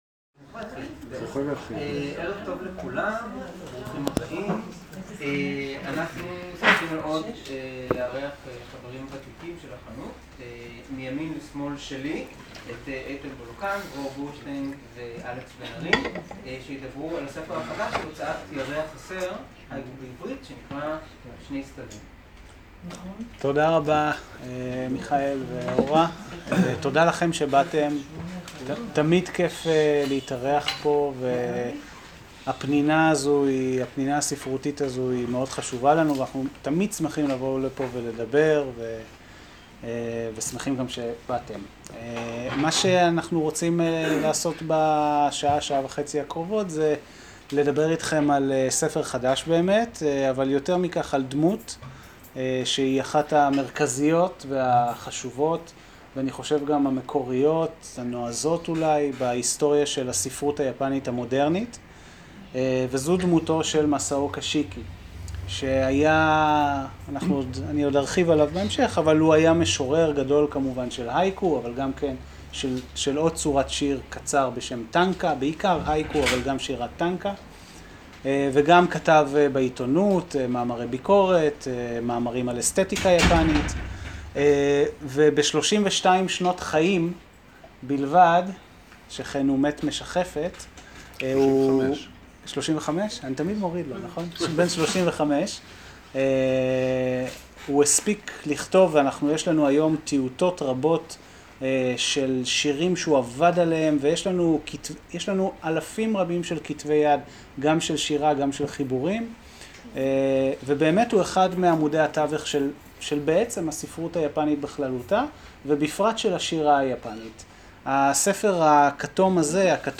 הקלטת ערב ההשקה לספר "שני סתווים"
מוזמנים/ות להאזין להקלטת ערב ההשקה לכבוד הספר "שני סתווים" – אוסף תרגומי המשורר מסאוקה שיקי, שנערך אתמול בחנות הספרים "מילתא" שברחובות.